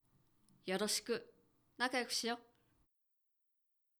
ボイス
ダウンロード 中性_「よろしく、仲良くしよ」
中性挨拶